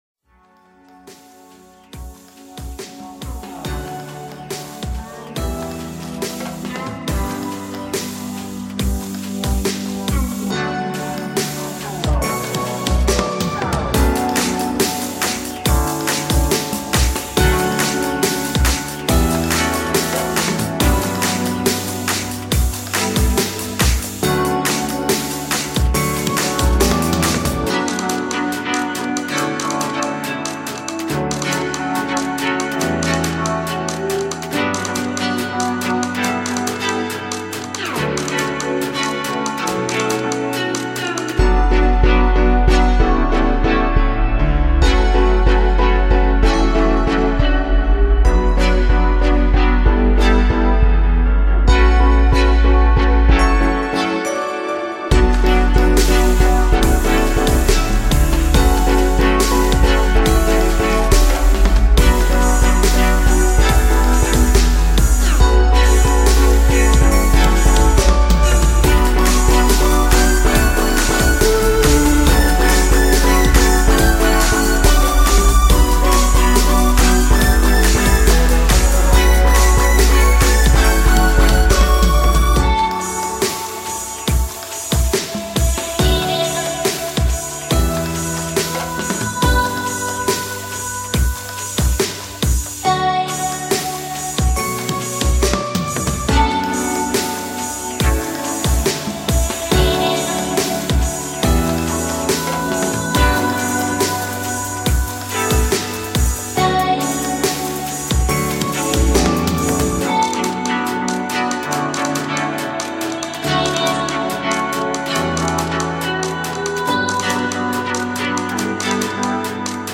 BPM: 139 Key: Fmin Time Spent